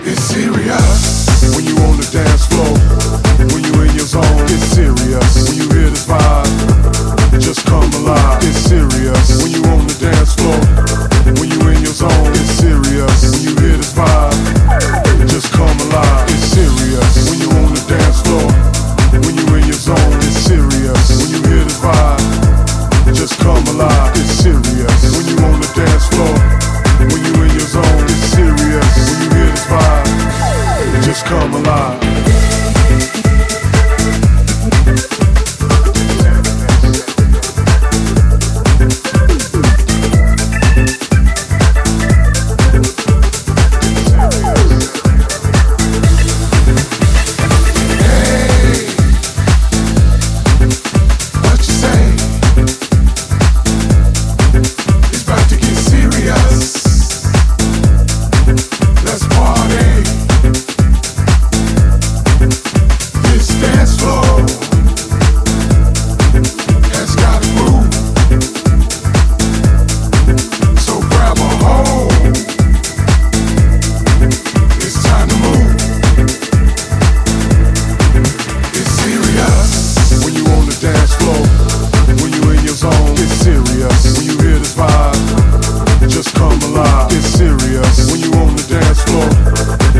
disco, funk and groove-laden
signature improvisatory flow is front and centre